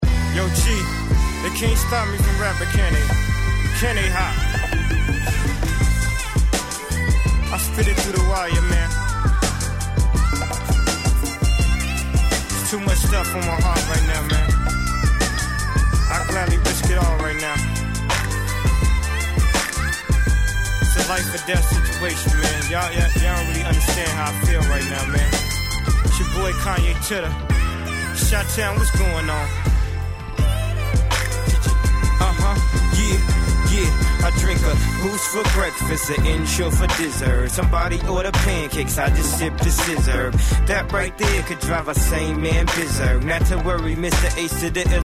Hip HopのPartyでは未だにPlayされればしっかり盛り上がる1曲です。